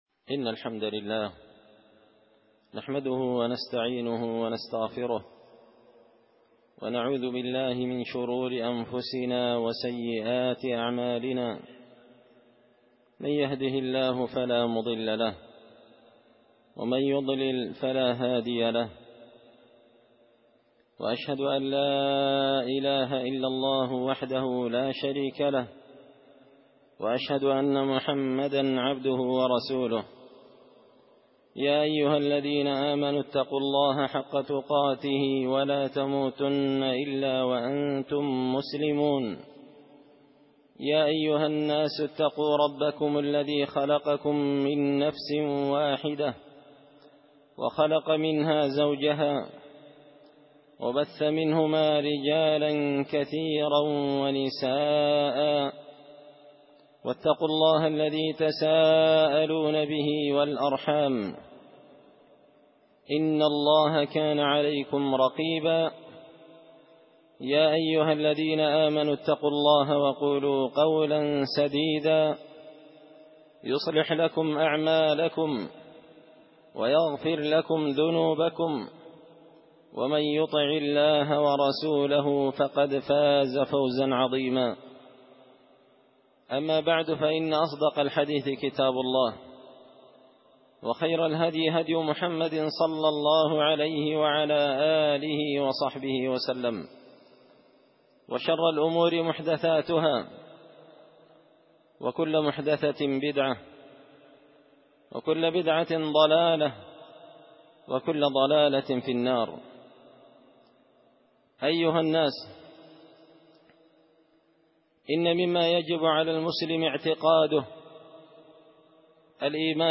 خطبة جمعة بعنوان – صفة العلو للعلي الغفار
دار الحديث بمسجد الفرقان ـ قشن ـ المهرة ـ اليمن
خطبة_جمعة_بعنوان_صفة_العلو_للعلي_الغفار_6جماد_أول_1443هـ.mp3